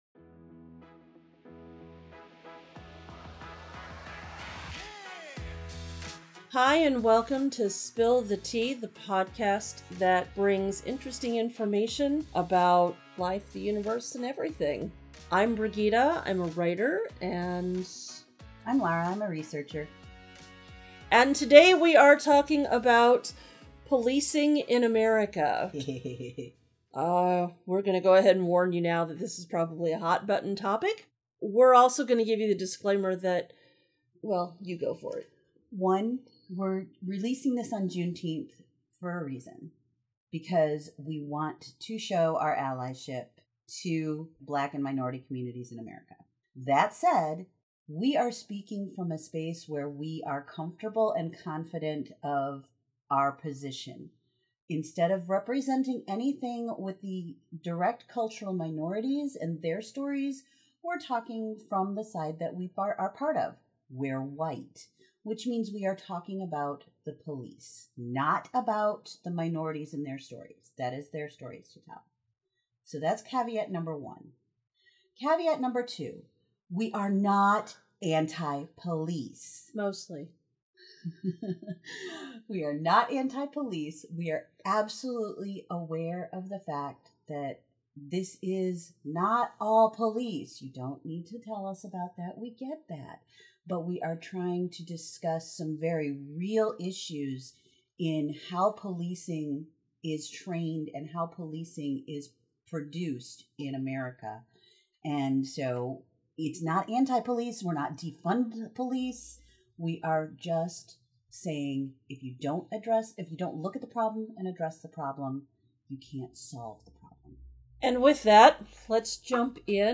(Note: This was recorded around Juneteenth; hence the references.)